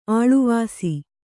♪ āḷuvāsi